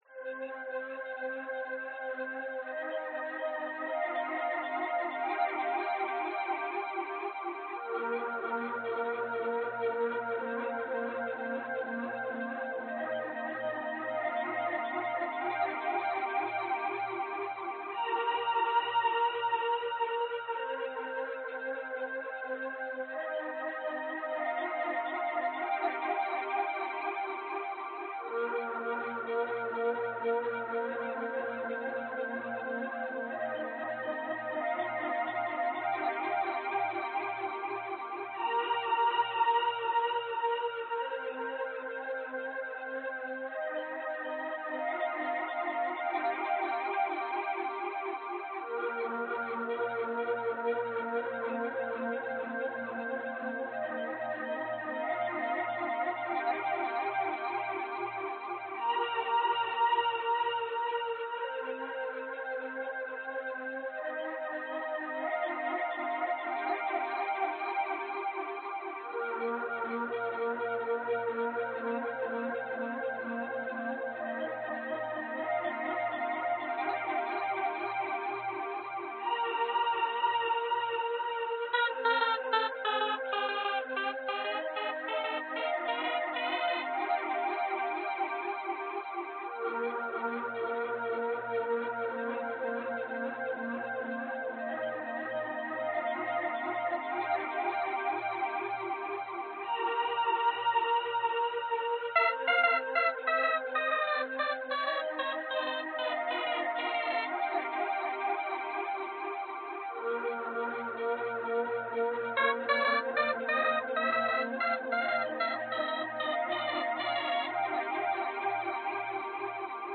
描述：反馈补丁是用纯数据制作的。有点像工业类型的音景。阴森恐怖
标签： 氛围 高铈 机械的 纯数据 花茎 合成器 合成
声道立体声